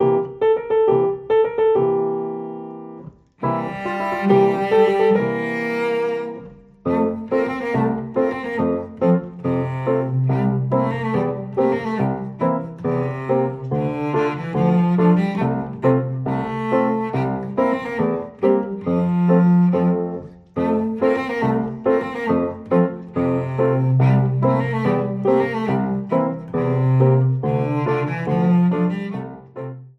Besetzung: Violoncello